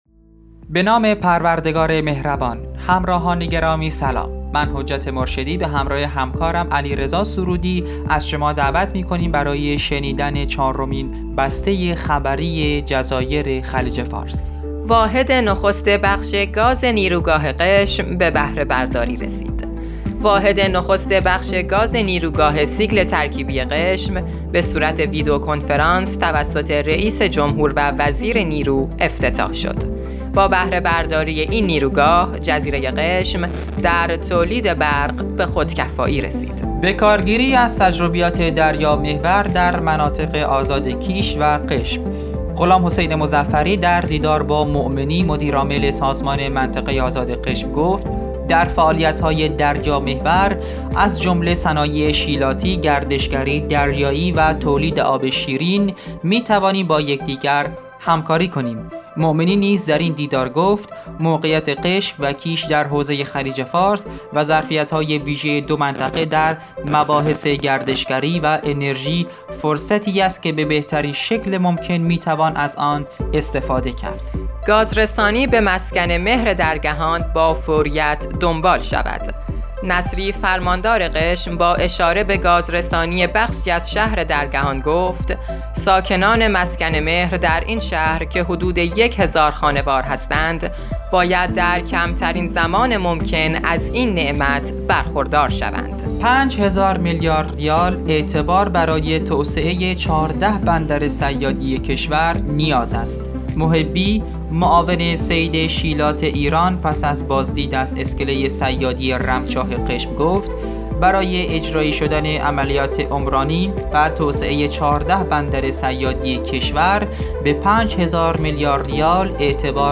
به گزارش خبرگزاری تسنیم از قشم، چهارمین بسته خبری رادیو تسنیم از جزایر خلیج فارس را با خبرهایی چون واحد نخست بخش گاز نیروگاه قشم به بهره‌برداری رسید، بکارگیری از تجربیات دریا محور در مناطق آزاد کیش و قشم، گاز رسانی به مسکن مهر درگهان با فوریت دنبال شود، 5 هزار میلیارد ریال اعتبار برای توسعه 14 بندر صیادی کشور نیاز است، مطالبات معوق تامین اجتماعی قشم از یک‌هزار و 135 میلیارد ریال فراتر رفت، مدیرعامل منطقه آزاد کیش عضو افتخاری هلال احمرشد منتشر شد.